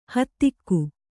♪ hattikku